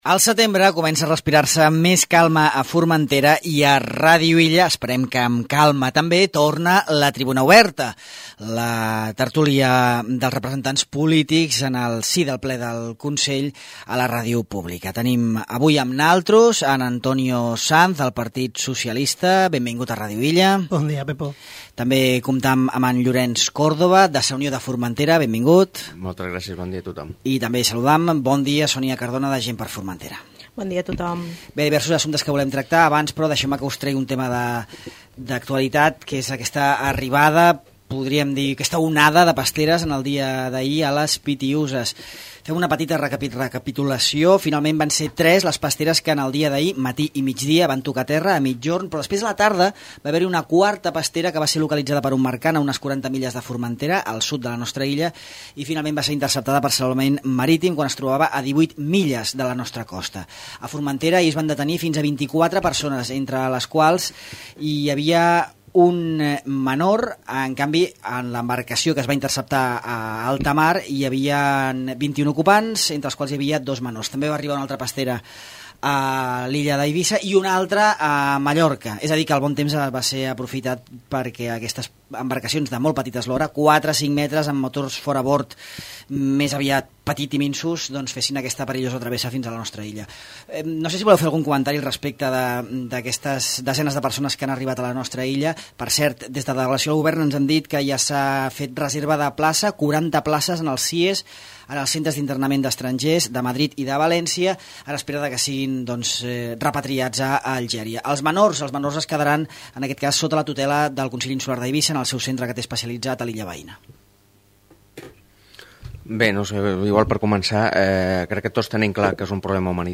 La tertúlia política aborda la contaminació de l'aire a la Savina